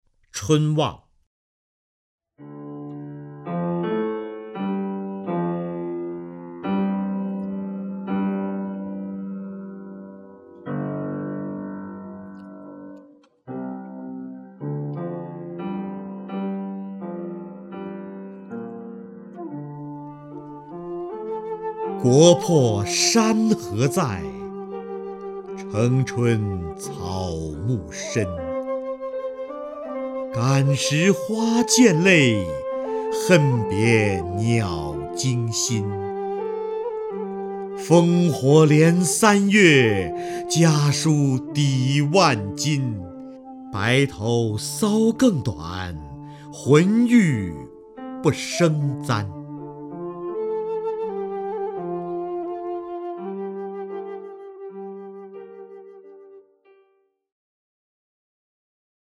瞿弦和朗诵：《春望》(（唐）杜甫) （唐）杜甫 名家朗诵欣赏瞿弦和 语文PLUS